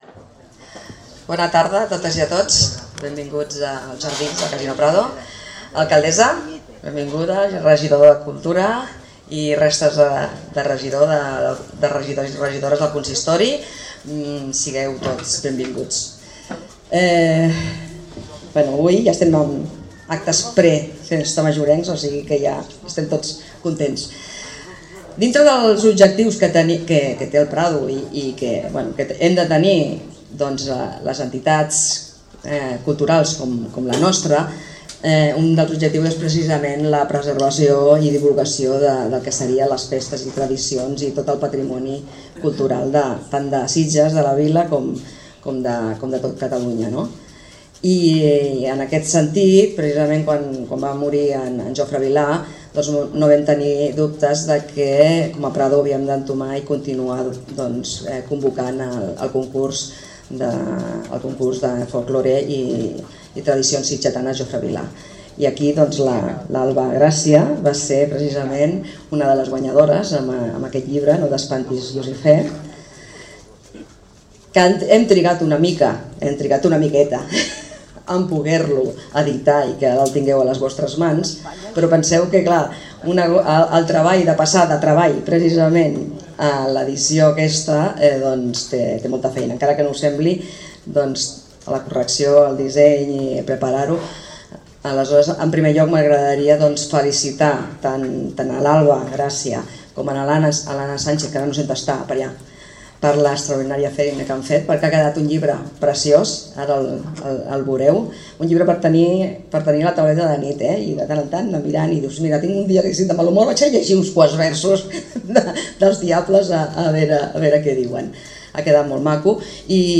I no ha estat una presentació tradicional, sinó un diàleg